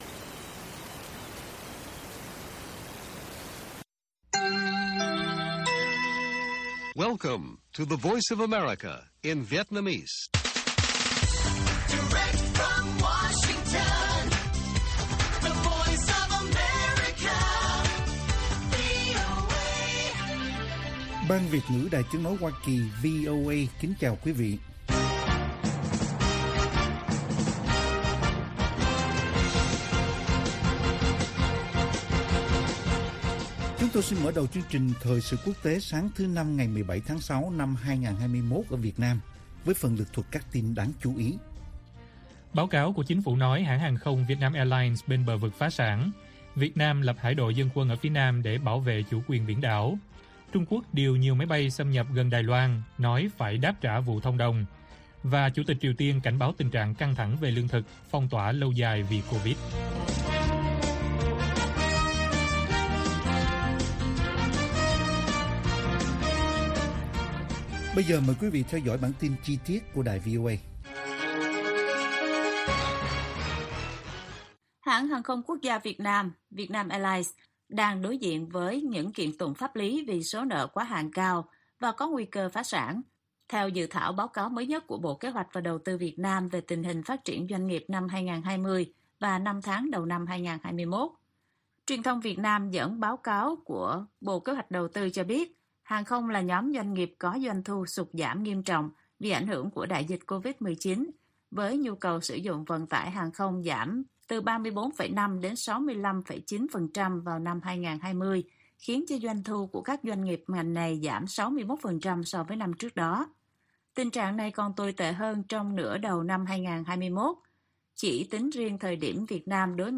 Bản tin VOA ngày 17/6/2021